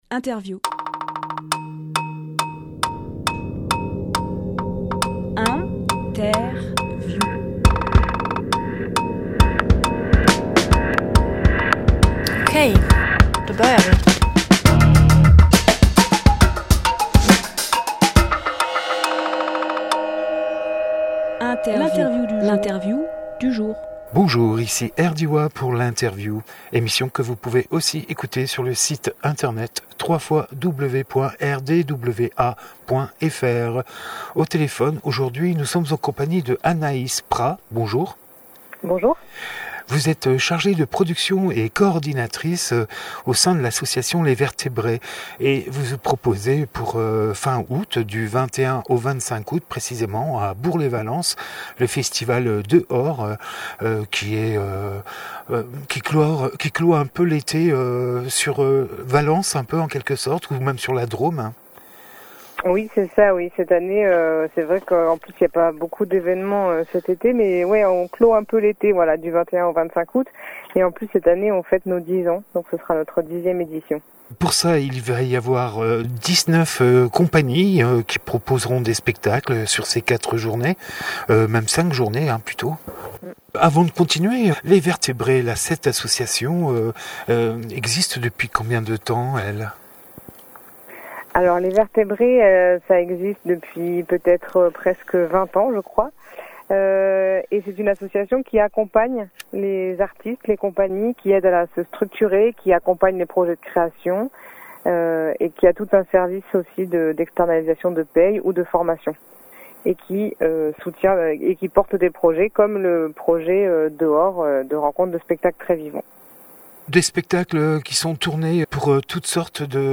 Emission - Interview Dehors ! 2020 Publié le 14 août 2020 Partager sur…
13.08.20 Lieu : Studio RDWA Durée